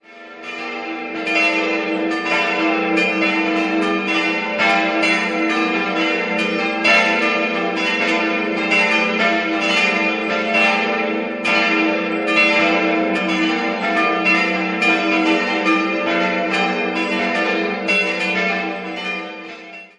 Jahrhunderts erfolgte bereits eine Erweiterung des Gotteshauses. 5-stimmiges Geläute: e'-g'-a'-h'-d'' Alle Glocken wurden von der Firma Grassmayr in Innsbruck gegossen, die drei mittleren 1955, die große und die kleine im Jahr 1923.